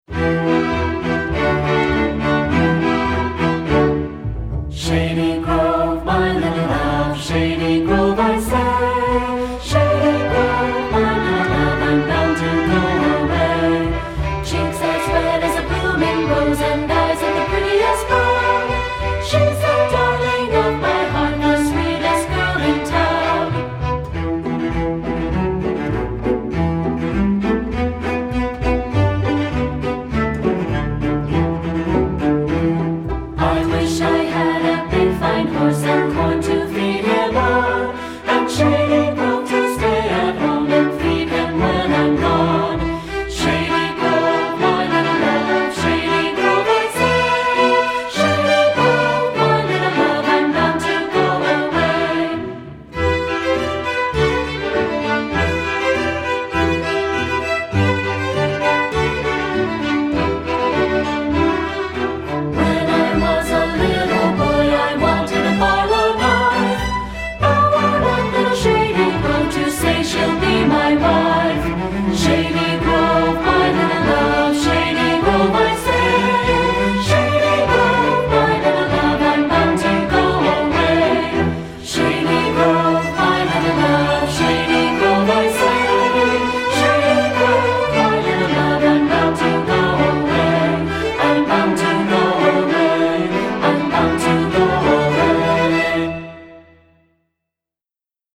Instrumentation: string orchestra
folk